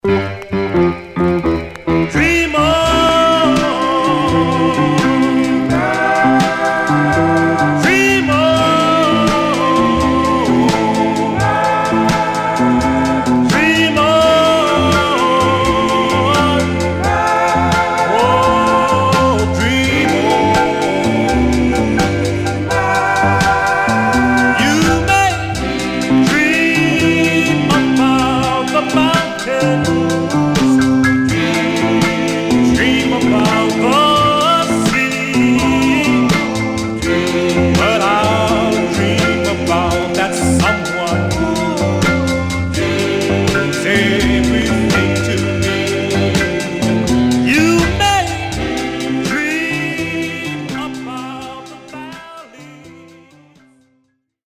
Stereo/mono Mono
Male Black Group